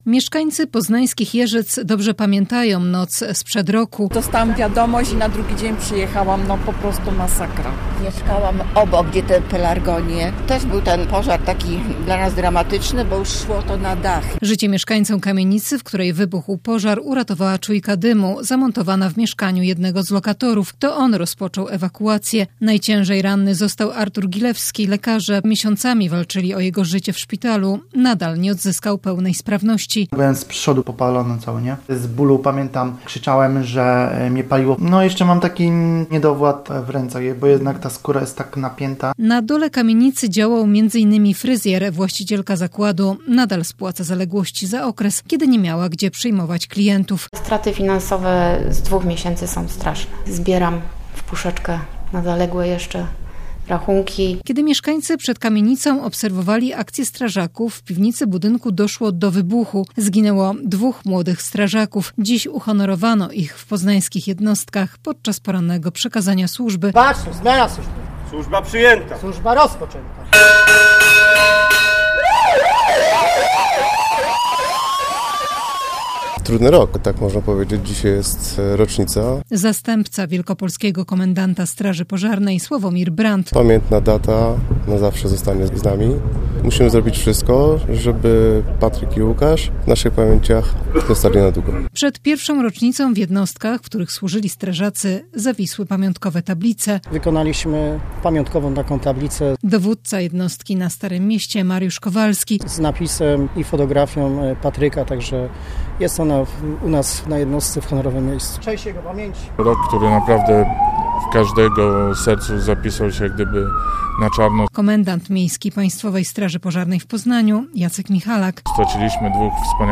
Syreny odezwały się rano w jednostkach strażaków w Poznaniu. W ten sposób ratownicy uczcili pamięć dwóch kolegów, którzy przed rokiem zginęli w wybuchu w kamienicy przy Kraszewskiego.
apel pamięci kraszewskiego